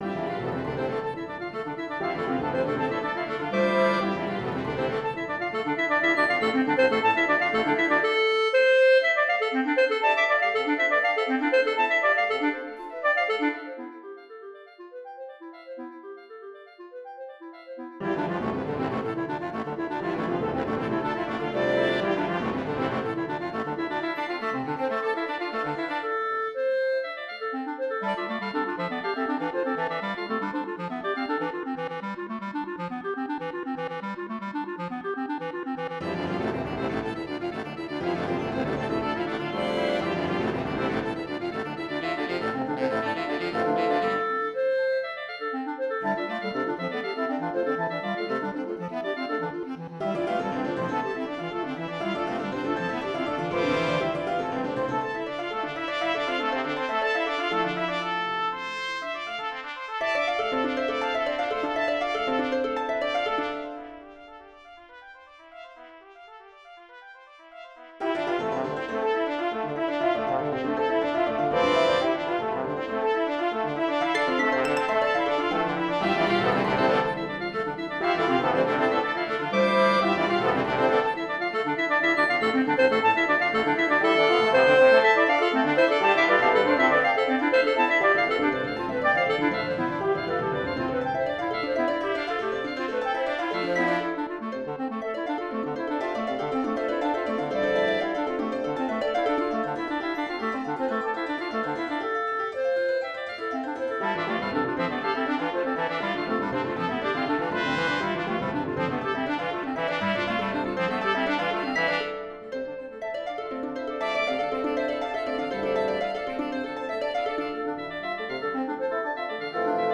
It almost establishes a fun little rhythmic pattern in the background, but again: it was not the computer that created this piece. On the other hand, it was the computer that allowed me to make the sounds.
ELECTRO MUSIC; COMPUTER MUSIC